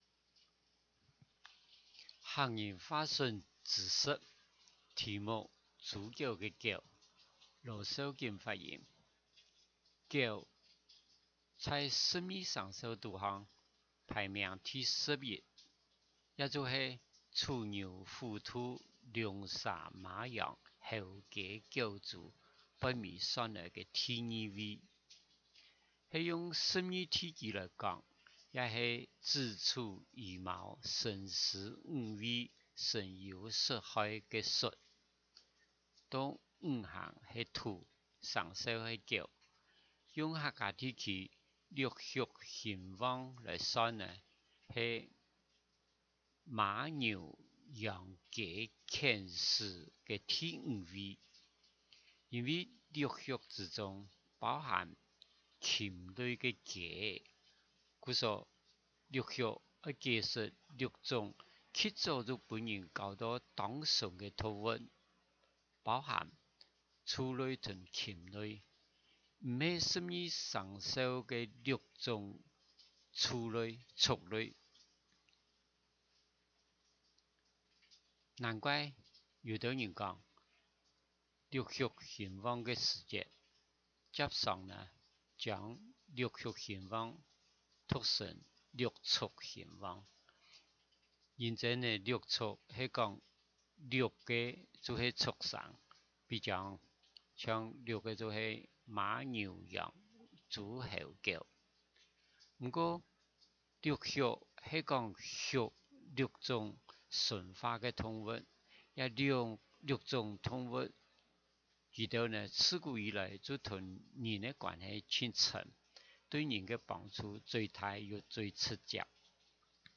豬狗个狗 （四縣腔）